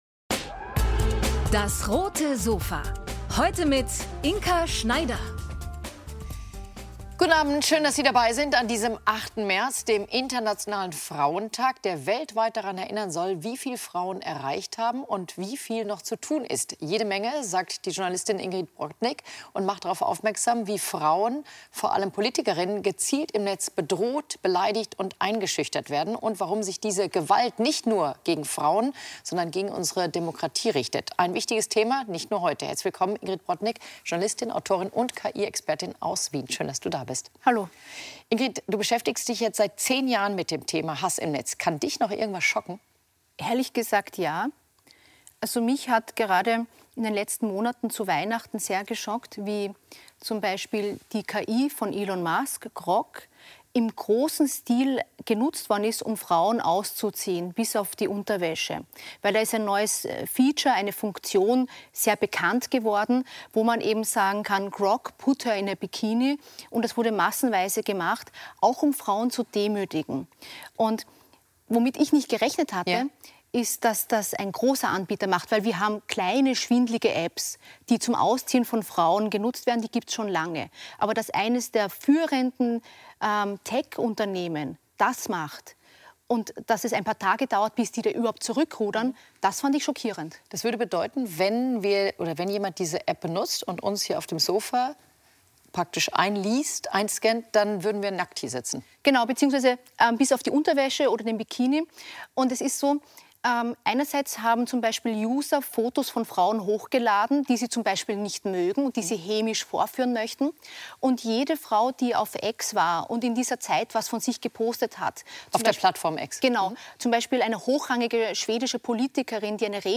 DAS! - täglich ein Interview Podcast